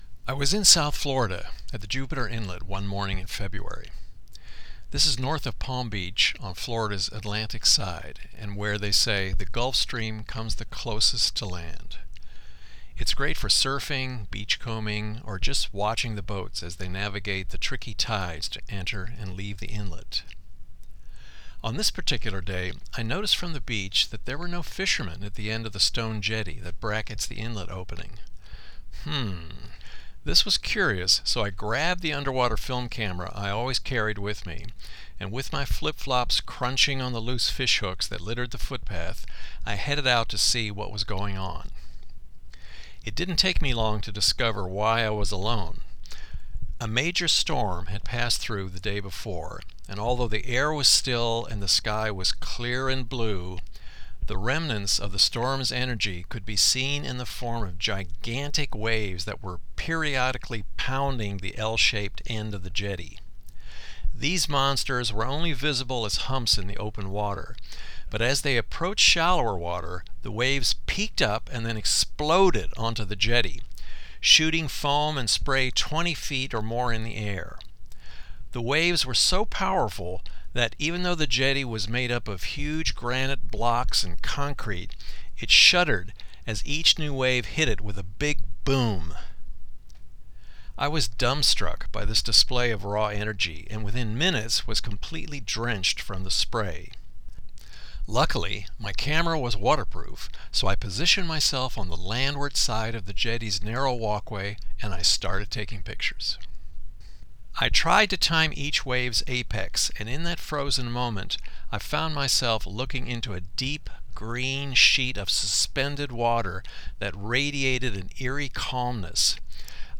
A Pelican Salute (audio story)